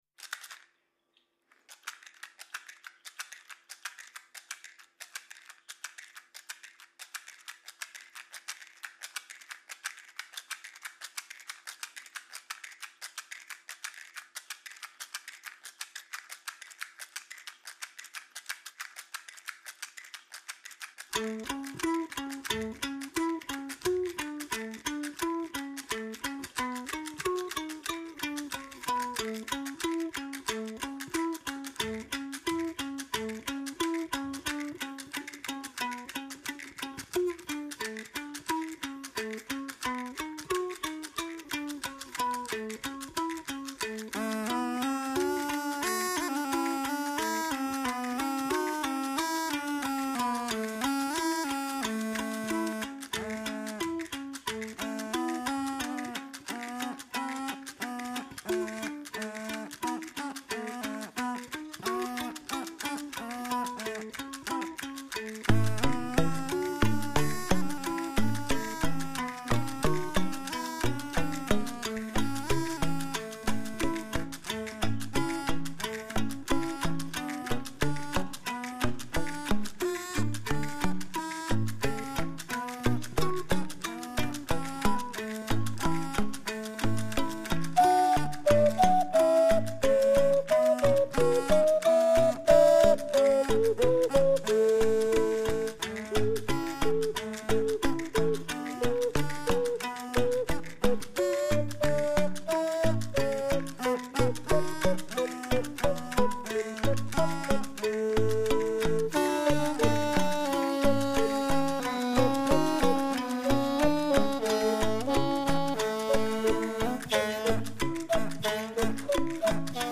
Fonogramm alkab esimesest rütmipillist - tikkutopsist
(esimene salm on rääkides).